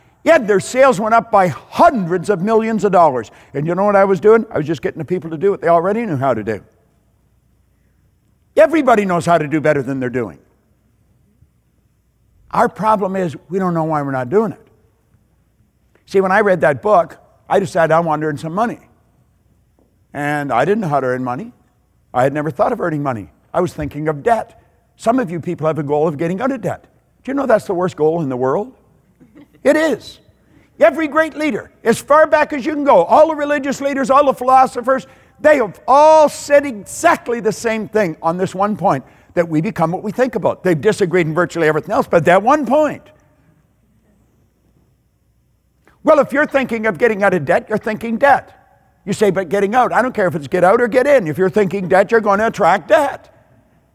Hear Bob Proctor Explain The Worst Goal in the World